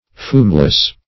Fumeless \Fume"less\, a. Free from fumes.